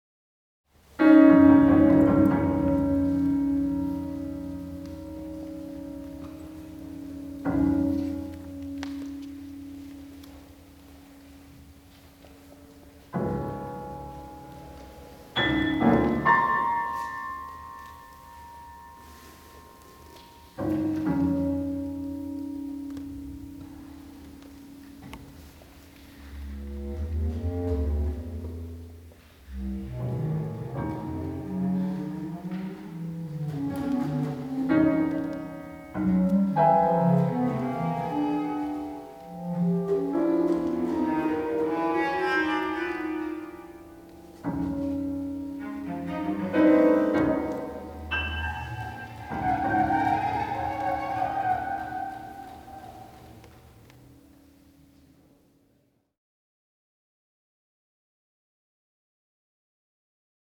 Final concert of 27.09.2005
Live recording